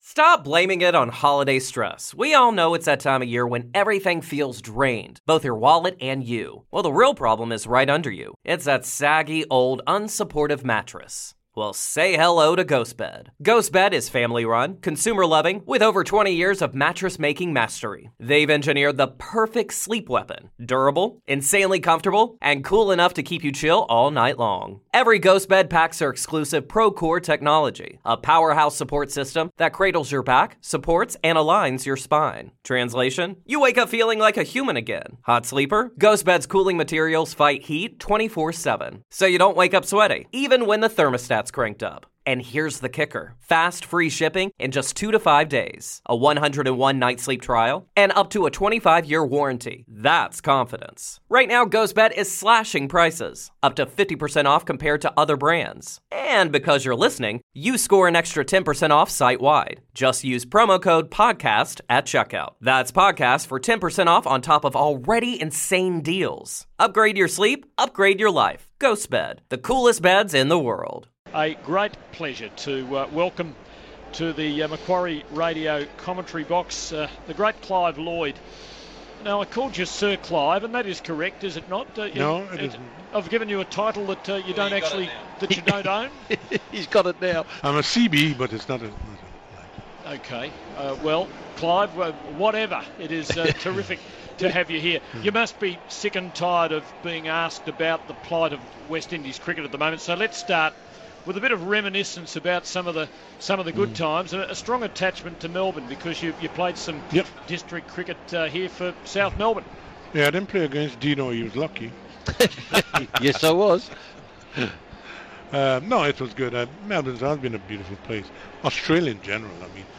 Clive Lloyd in the commentary box with Tim Lane, Tom Moody and Dean JOnes